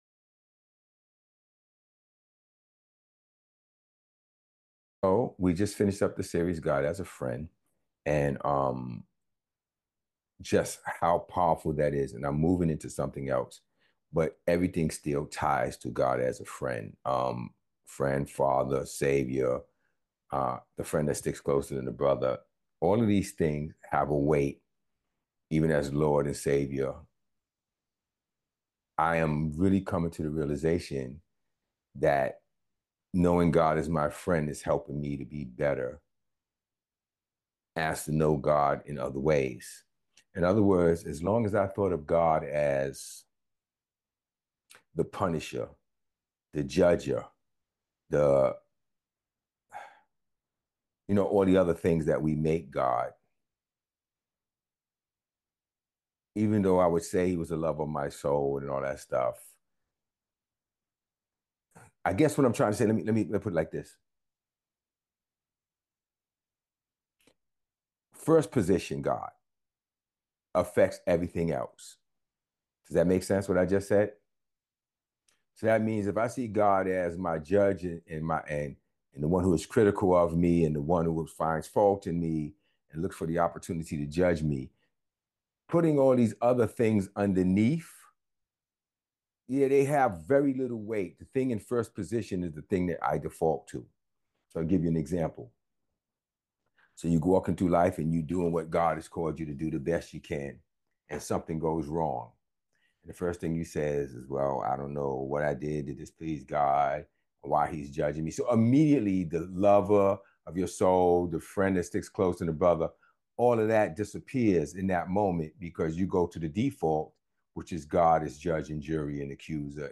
Hebrews 4:11-12 Description: In this weeks’ message, we learned that rest is the key ingredient to be led by and hear God.